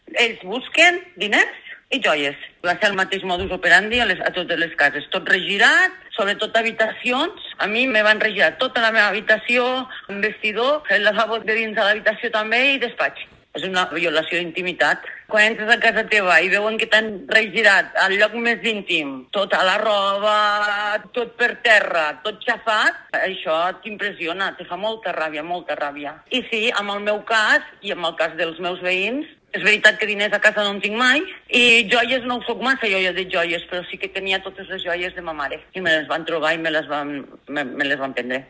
Blanca López detalla com va ser el seu robatori